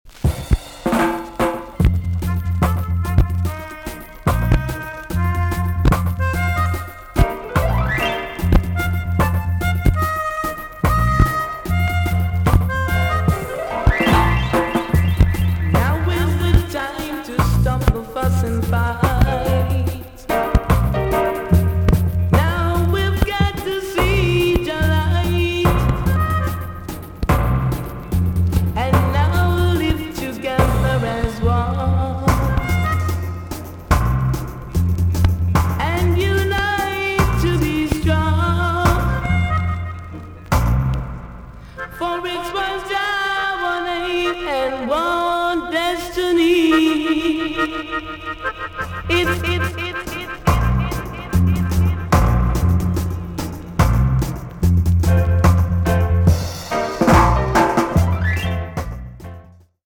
VG ok~EX- 前半キズでプチノイズが入ります。
REAL ROCK RIDDIM